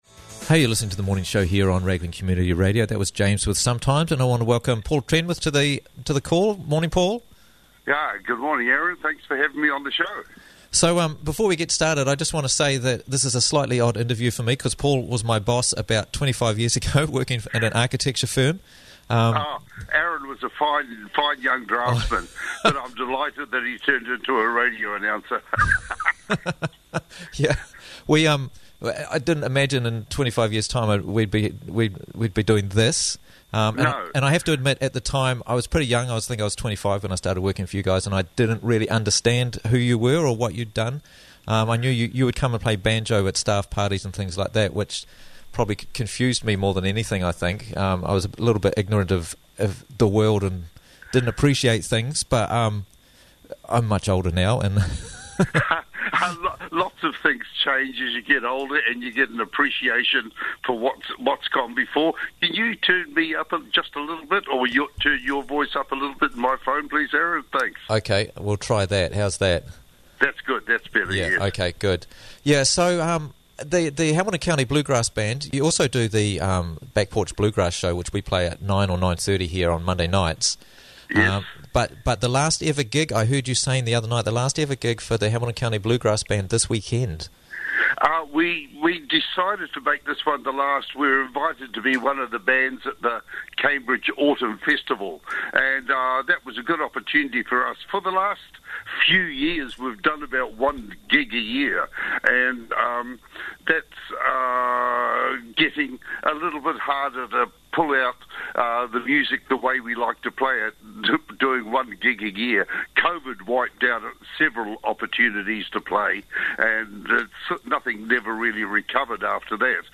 Hamilton County Bluegrass Band Last Concert - Interviews from the Raglan Morning Show